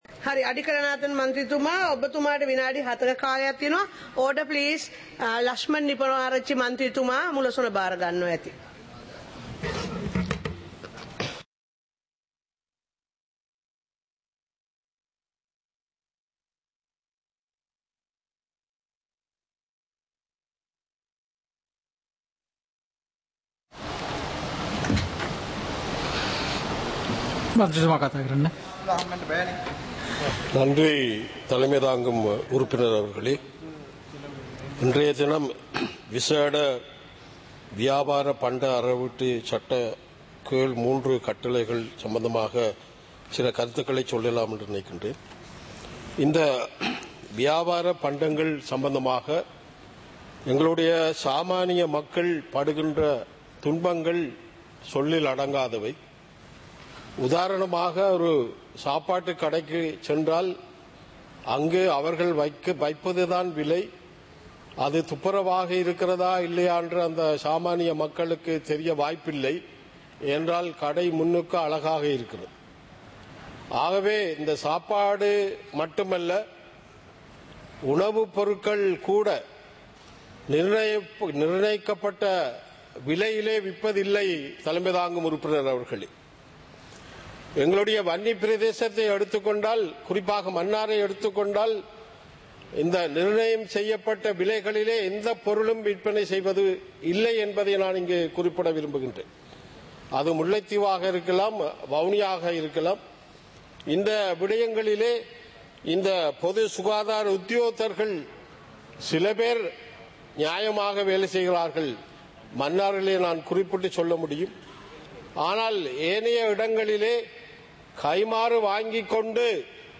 இலங்கை பாராளுமன்றம் - சபை நடவடிக்கைமுறை (2026-02-18)
நேரலை - பதிவுருத்தப்பட்ட